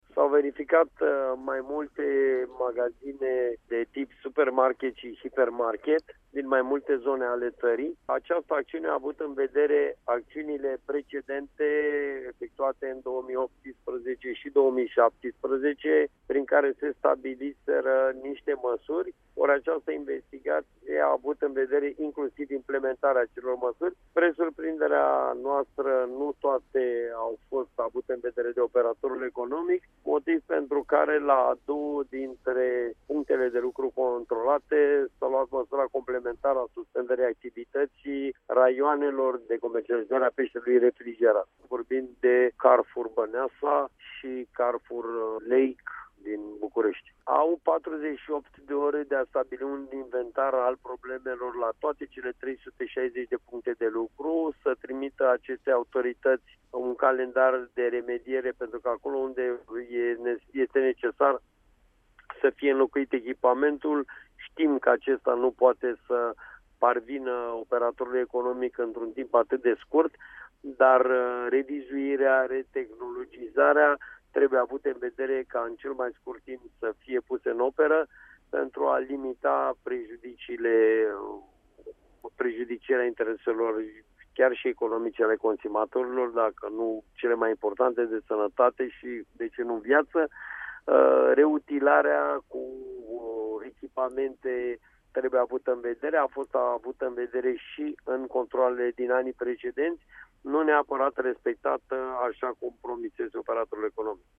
Vine cu precizări preşedintele ANPC, Horia Constantinescu: